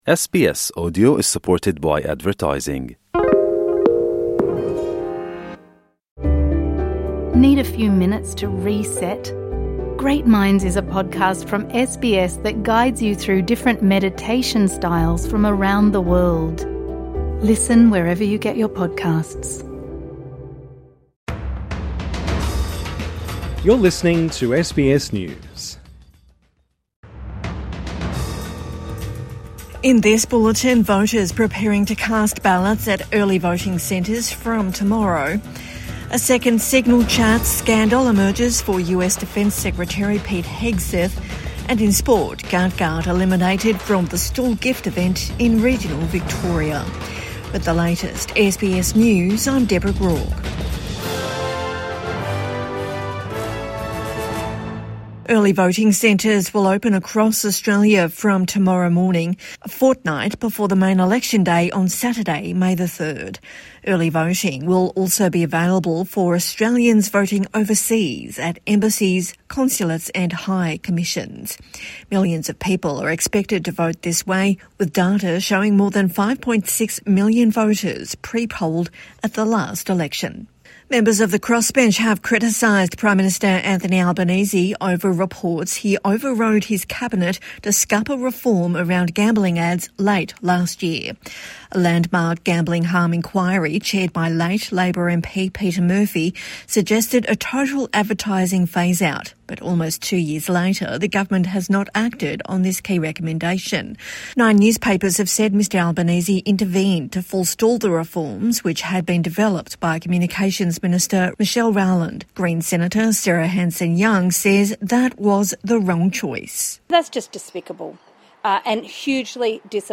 A bulletin of the day’s top stories from SBS News. Get a quick rundown of the latest headlines from Australia and the world, with fresh updates each morning, lunchtime and evening.